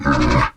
Minecraft Version Minecraft Version snapshot Latest Release | Latest Snapshot snapshot / assets / minecraft / sounds / mob / camel / ambient1.ogg Compare With Compare With Latest Release | Latest Snapshot